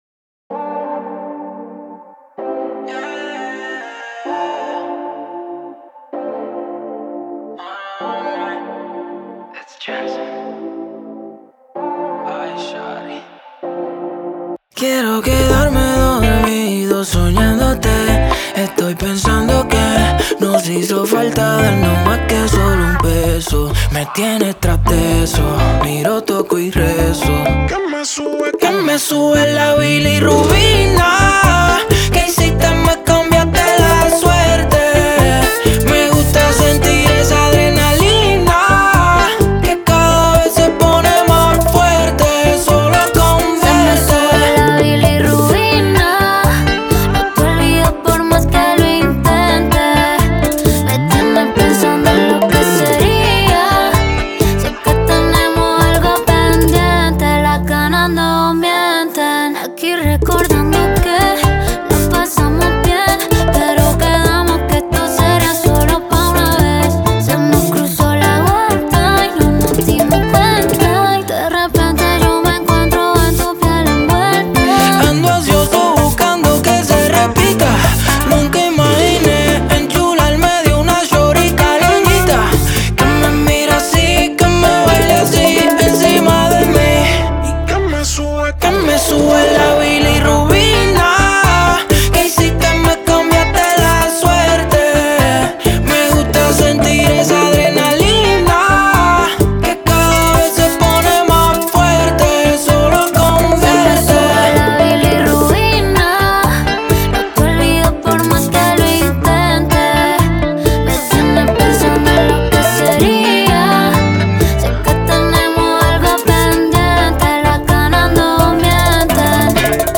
la energía atrevida y sensual